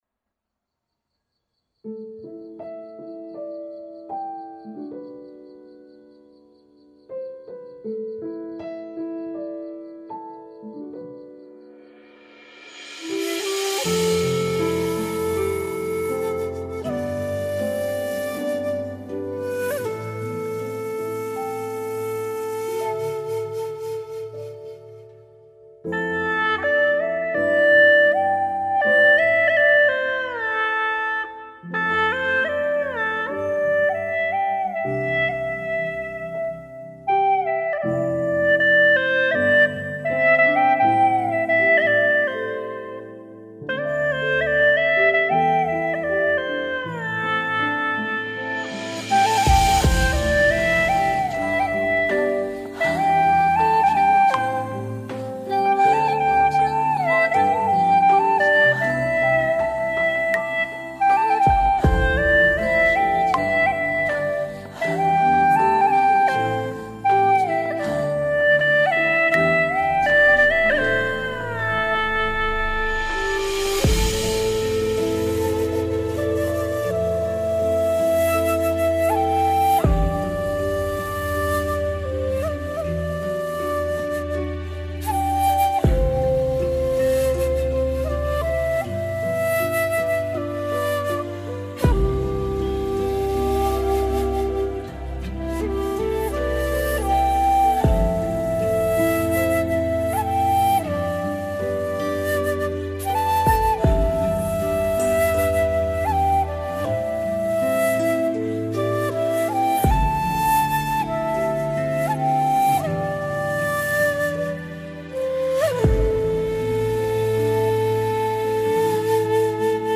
调式 : C 曲类 : 古风